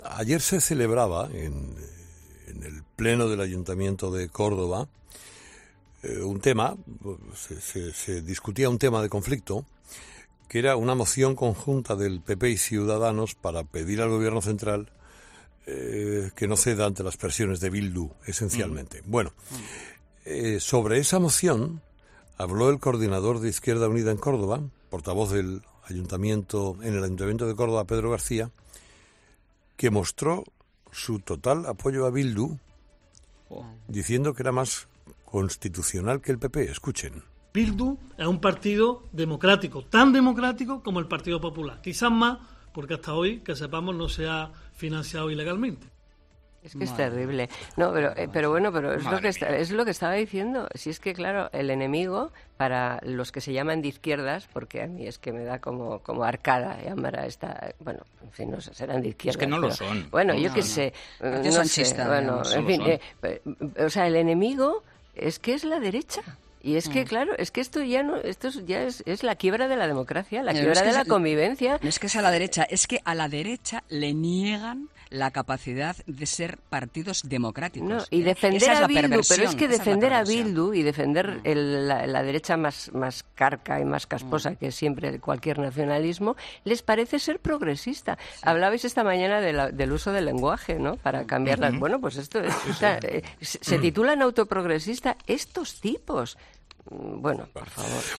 Durante el debate, el coordinador de IU en Córdoba y portavoz en el ayuntamiento Pedro García llegó a asegurar que Bildu es "más democrático" que el PP. "Bildu es un partido democrático. Tan democrático como el PP. Quizá más porque que sepamos hasta hoy no se ha financiado ilegalmente", aseguró durante el pleno.